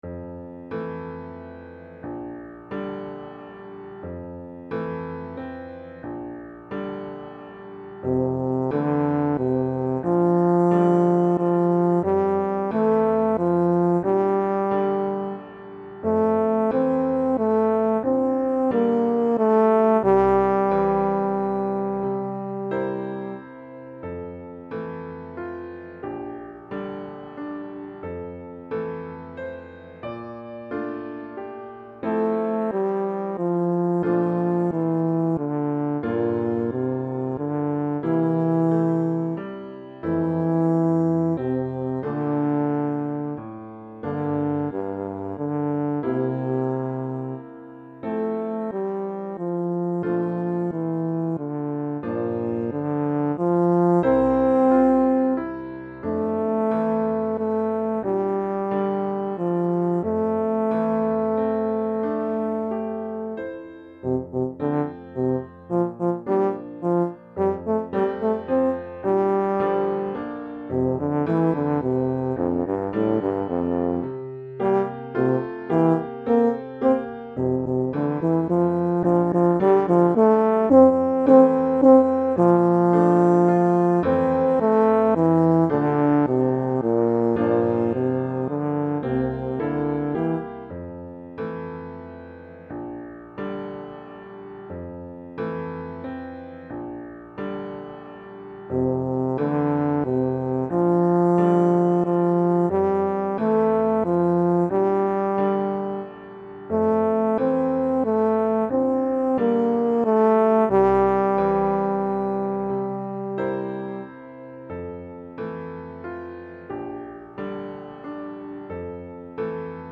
Répertoire pour Tuba, euphonium ou saxhorn